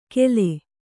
♪ kele